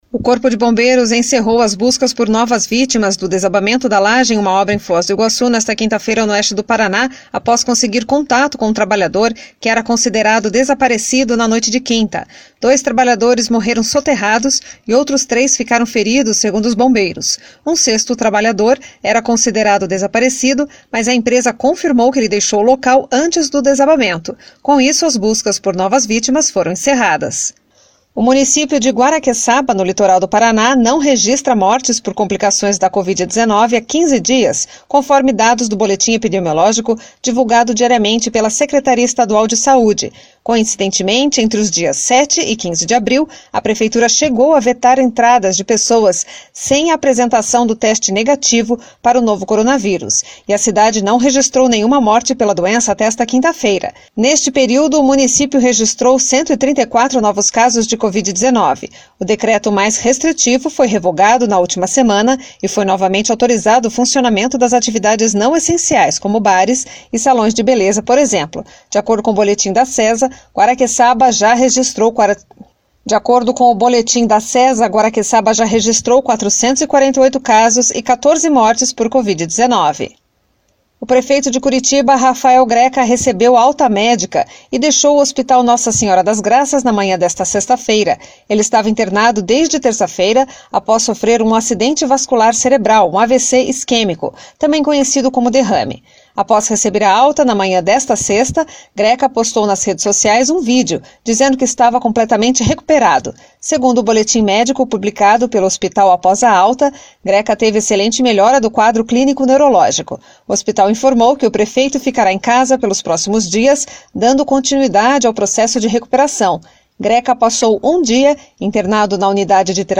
Giro de Notícias Tarde SEM TRILHA